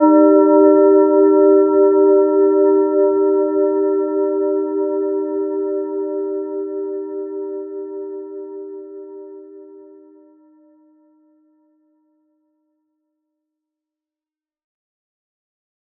Gentle-Metallic-2-G4-mf.wav